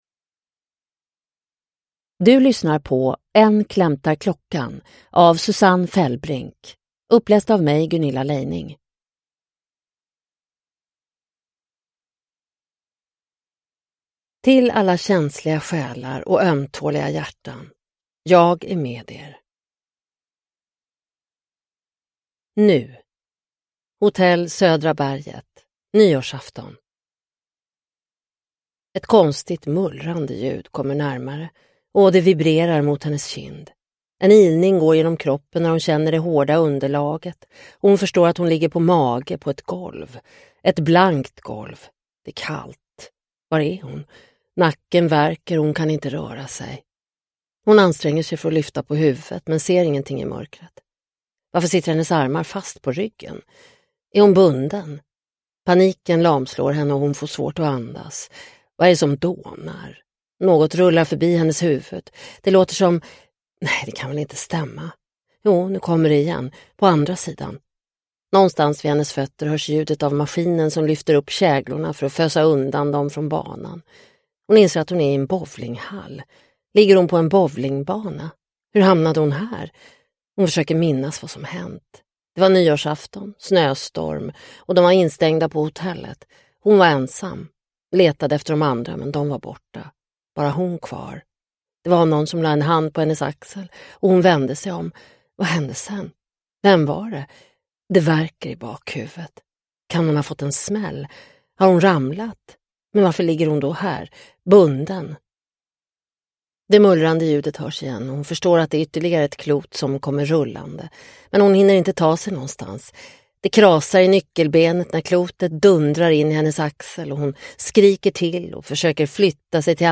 Än klämtar klockan – Ljudbok – Laddas ner